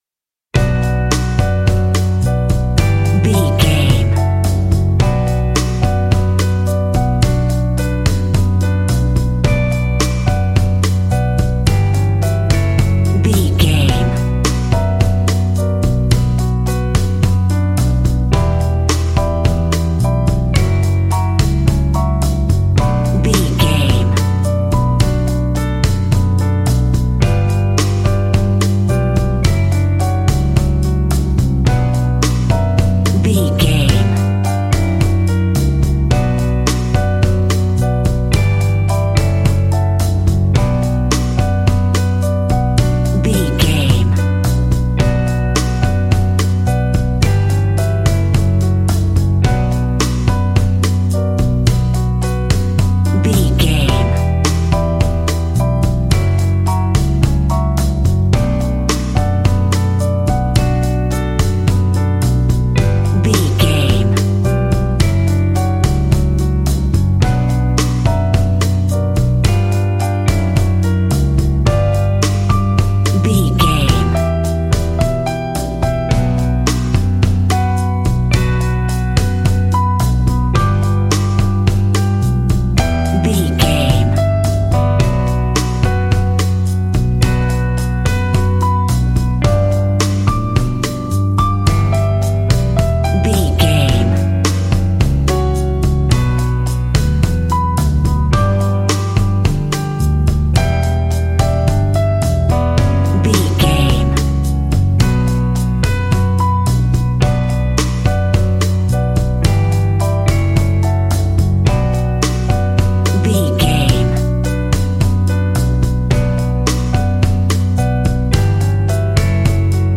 Aeolian/Minor
DOES THIS CLIP CONTAINS LYRICS OR HUMAN VOICE?
flamenco
maracas
percussion spanish guitar